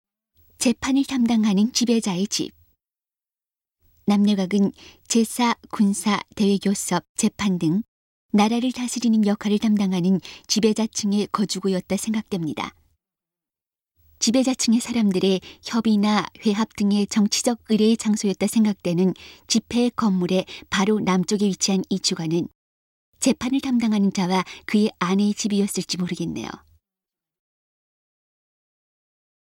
지배층 사람들의 협의나 회합 등의 정치적인 의례의 장소였다고 생각되는 「집회의 건물」 바로 남쪽에 위치한 이 주거는 재판을 담당하는 자와 그 아내의 집이었을지도 모르겠네요. 음성 가이드 이전 페이지 다음 페이지 휴대전화 가이드 처음으로 (C)YOSHINOGARI HISTORICAL PARK